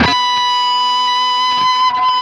LEAD B 4 CUT.wav